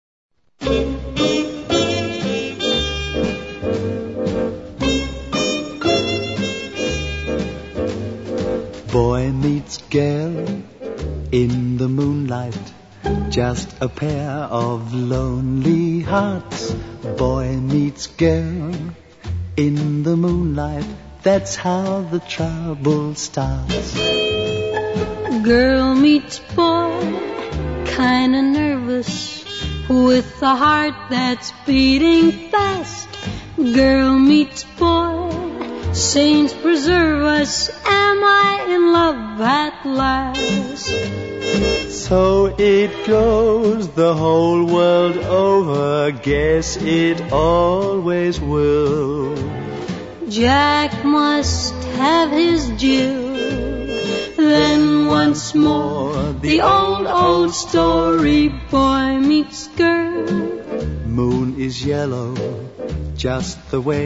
duet with unknown male